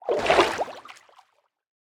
minecraft / sounds / liquid / swim14.ogg
swim14.ogg